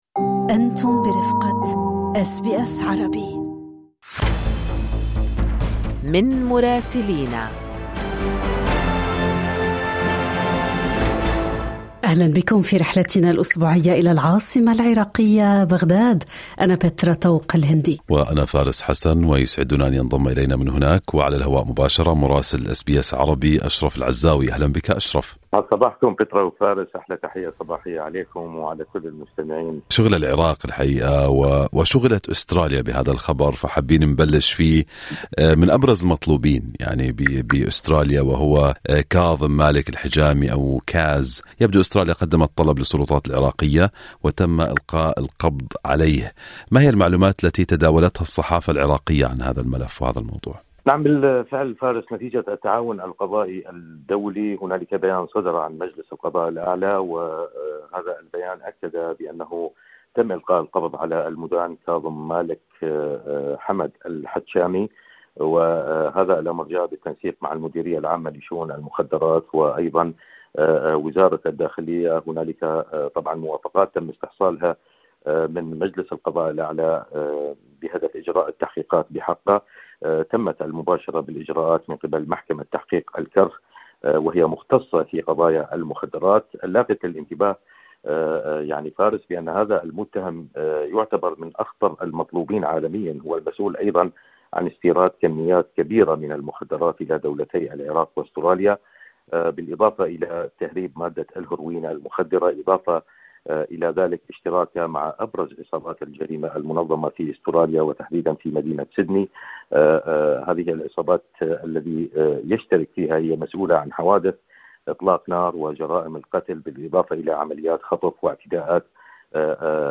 في تقريره الأسبوعي من بغداد